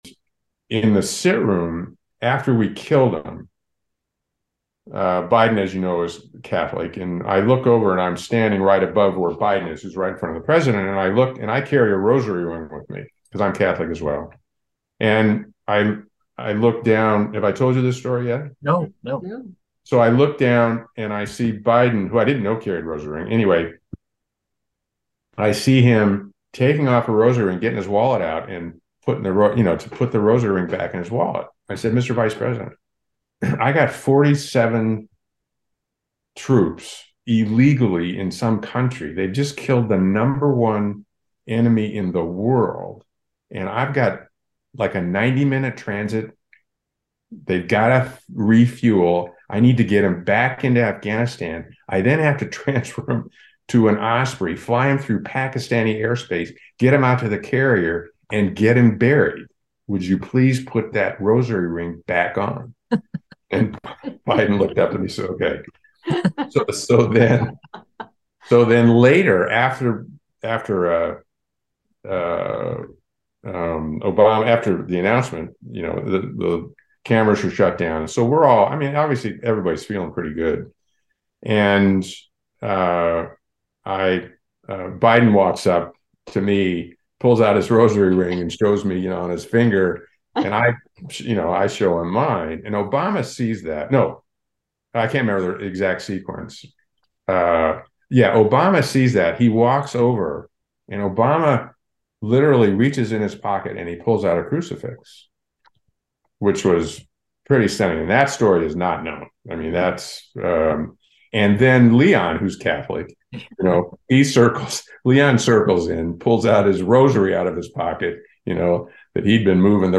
Chairman of the Joint Chiefs of Staff Mike Mullen describes his interactions with Vice President Joseph Biden, President Barack Obama, and Central Intelligence Agency Director Leon Panetta after bin Laden was killed.